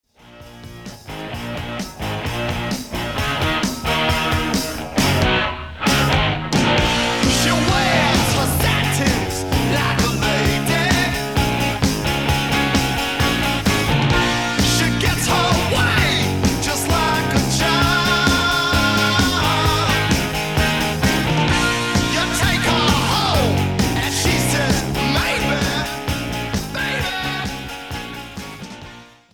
Genre:Soft Rock
came this ballad that the hardcore fans disproved of